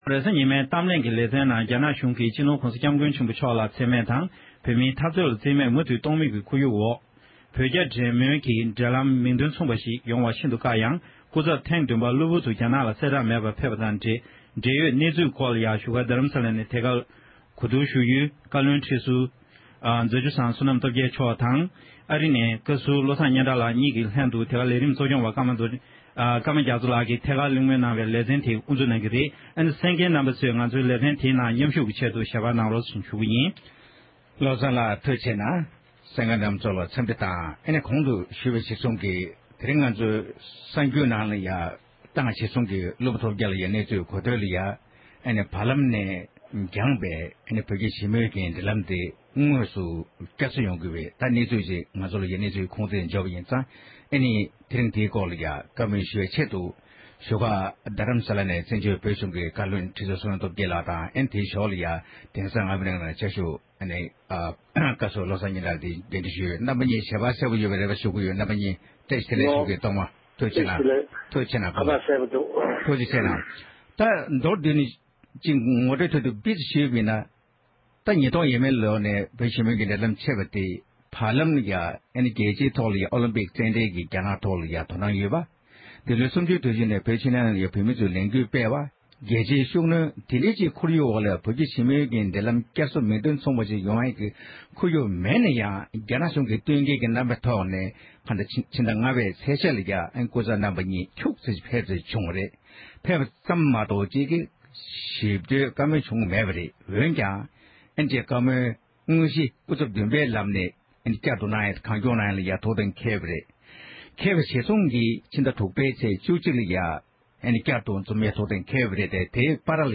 བགྲོ་གླེང་གནང་བ་ཞིག་གསན་རོགས་གནང༌༎